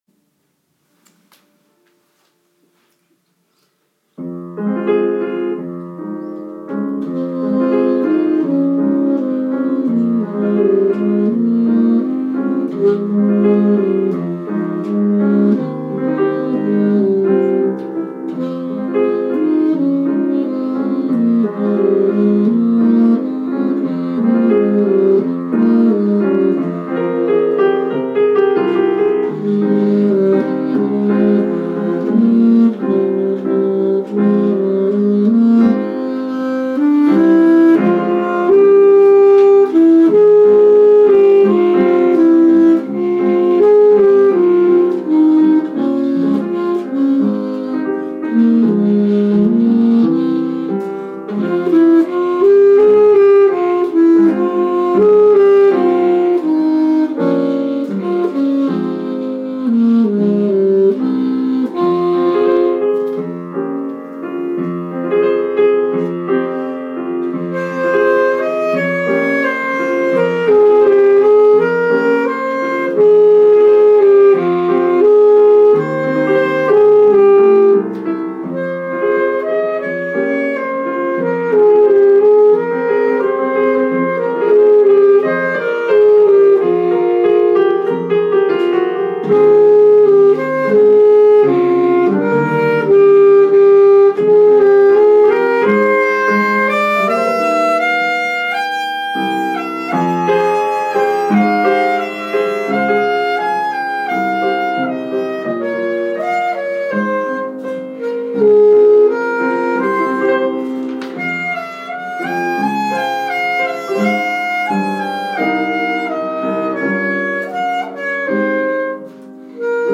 Solo Performance